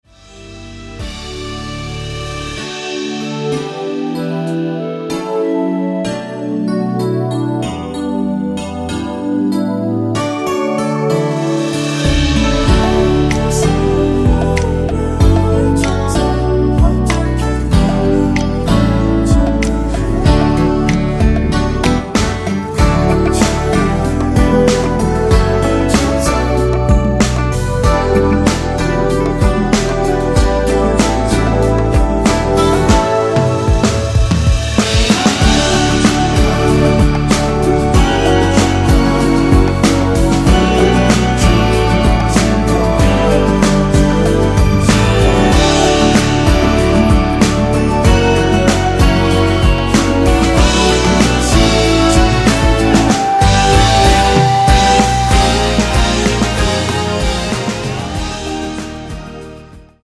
원키에서(-2)내린 멜로디와 코러스 포함된 MR입니다.(미리듣기 참고)
앞부분30초, 뒷부분30초씩 편집해서 올려 드리고 있습니다.